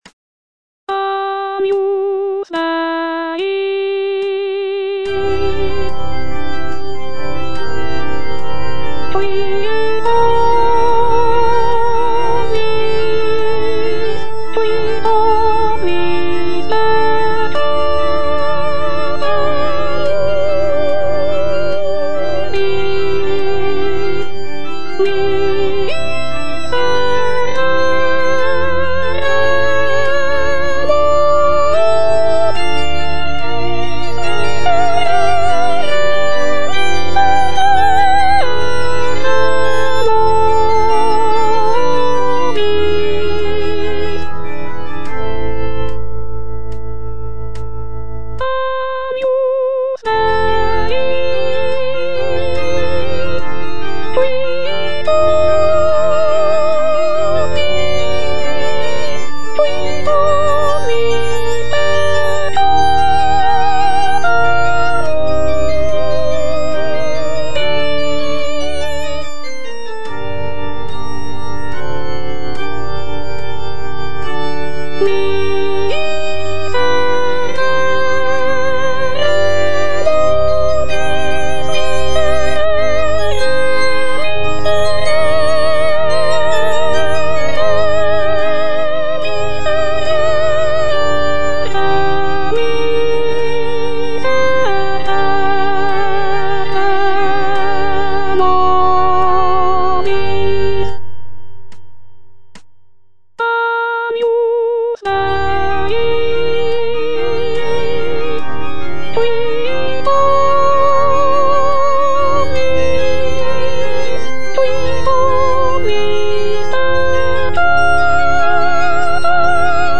J.G. RHEINBERGER - MASS IN C OP. 169 Agnus Dei - Soprano (Voice with metronome) Ads stop: auto-stop